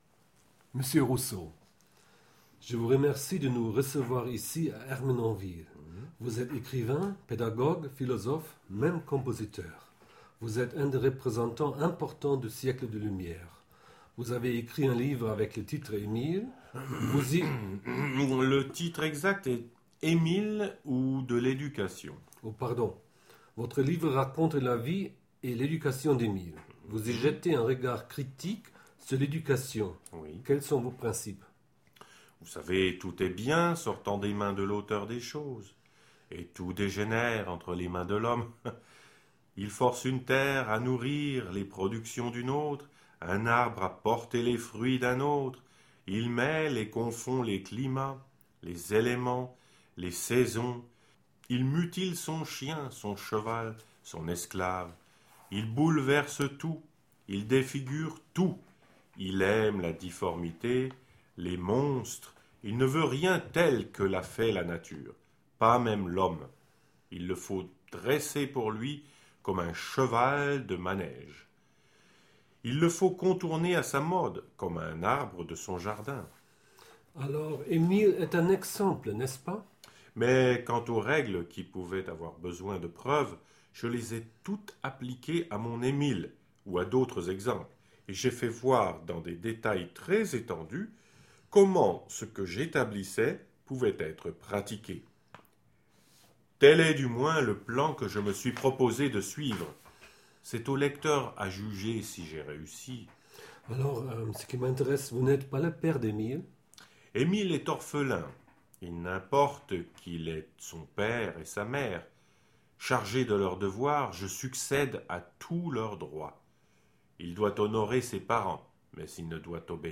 Il y a quelques jours, nous avons eu la chance d’être reçus par Jean-Jacques Rousseau à Ermenonville au nord de Paris.
Voilà l’interview – 30 minutes.